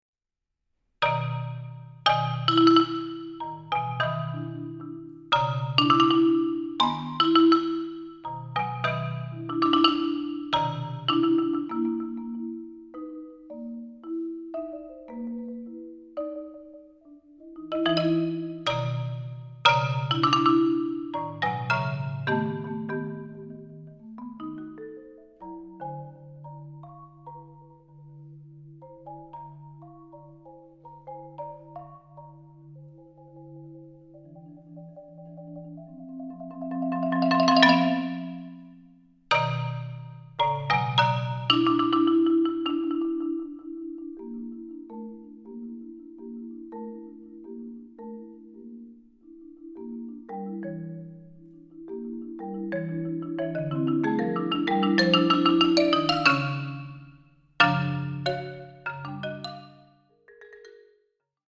Genre: Marimba (4-mallet)
Solo Marimba (4.5-octave)